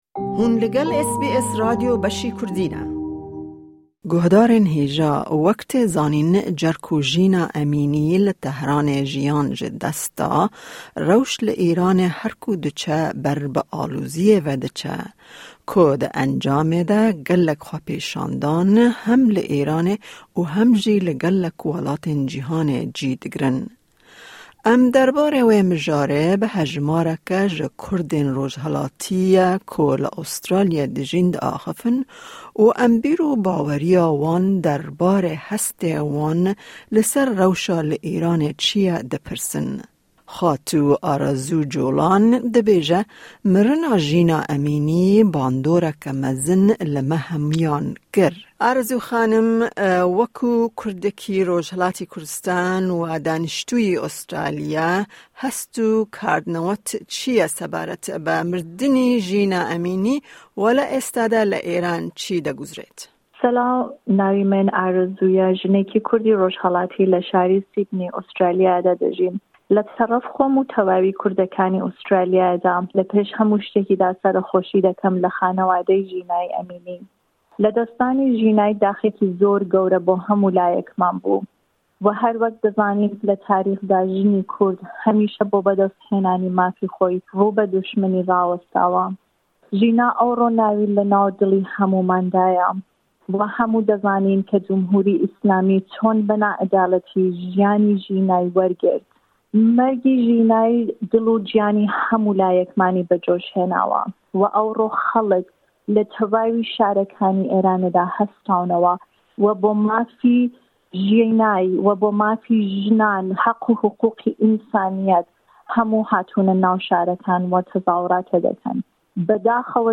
Cerku Jîna Emînî li Tehranê jiyan ji dest da rewş li Îranê herku diçe ber bi aloziyê ve diçe, ku di encamê de gelek xwepêşandan hem li Îranê û hem jî li gelek welatên cîhanê cî digirin. Em derbarê wê mijarê bi hejmareke ji Kurdên rojhilatîye ku li Australya dijîn dixafin û em bîr û baweriya wan derbarê hestê wan li ser rewşa li Îranê çî ye dipirsin.